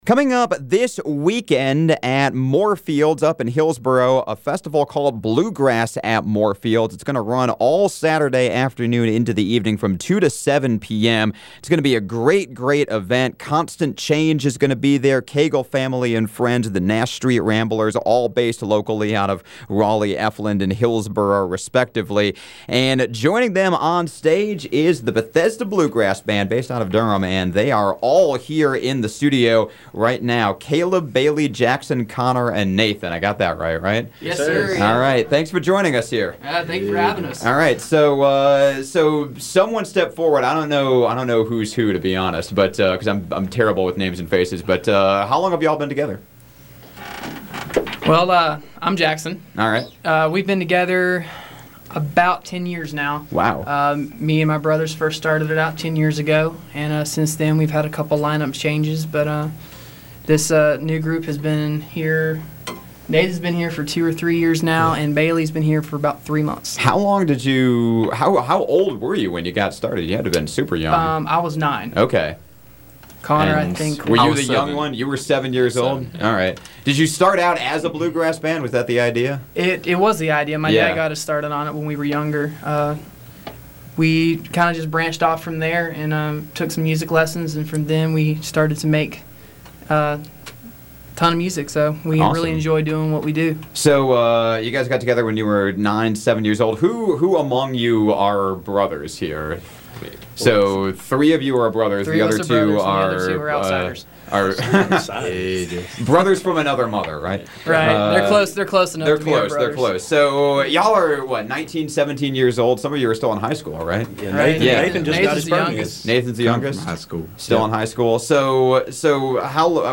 they brought all their instruments for a live performance